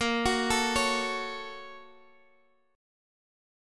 Gbm/Bb chord